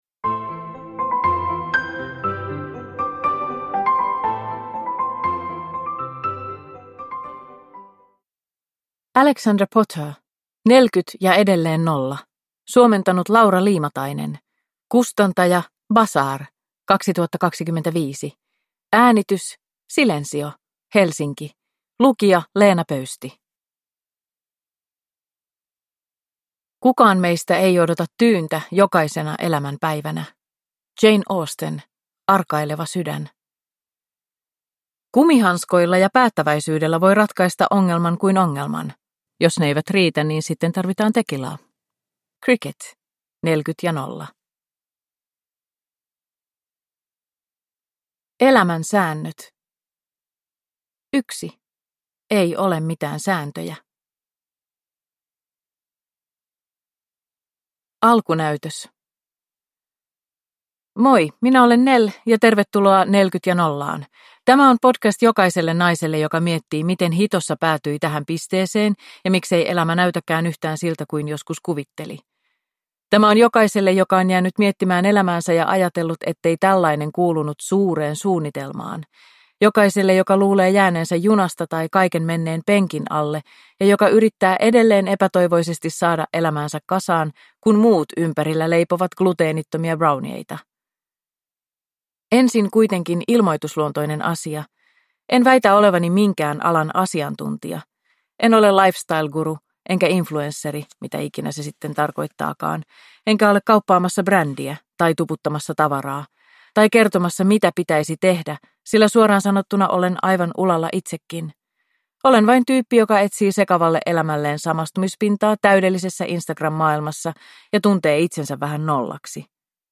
Nelkyt ja edelleen nolla (ljudbok) av Alexandra Potter